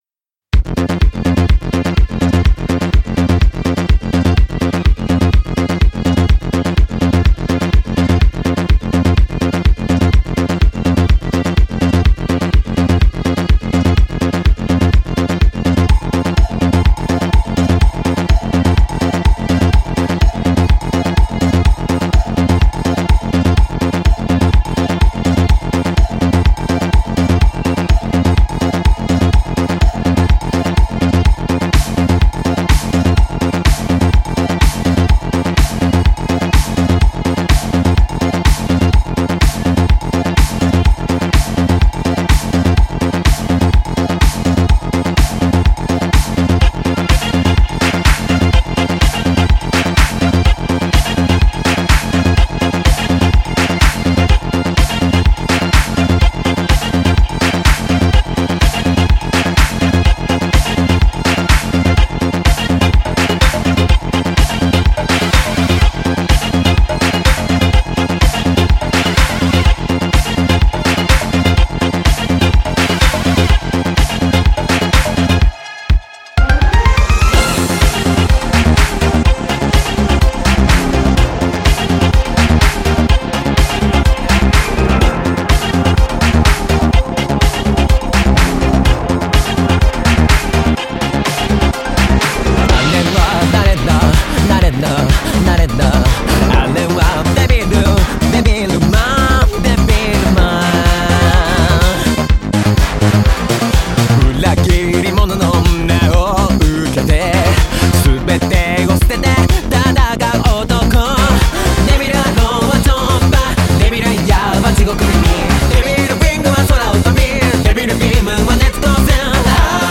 Night Version/Remix